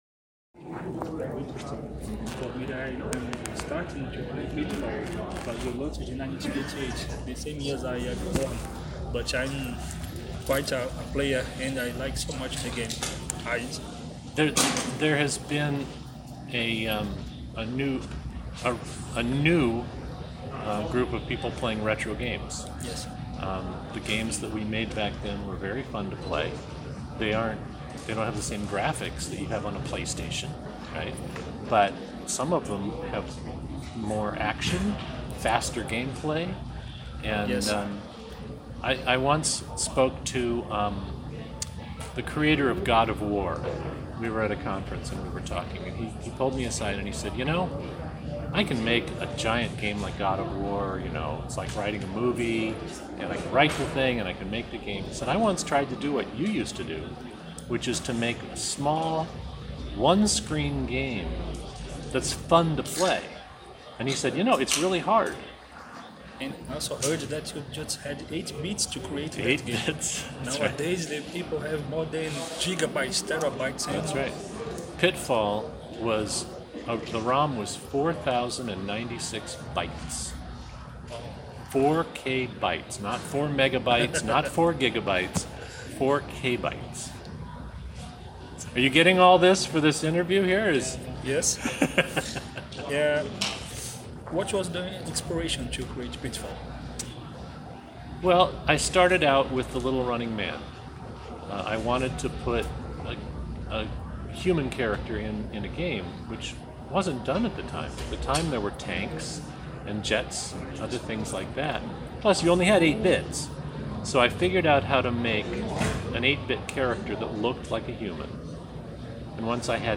BGS 2017 | Entrevistamos David Crane, criador de Pitfall e co-fundador da Activision
Fomos à Brasil Game Show 2017 e tivemos a oportunidade de entrevistar o icônico David Crane, criador do famoso Pitfall e um dos co-fundadores da Activision, uma das maiores publishers de jogos que existe.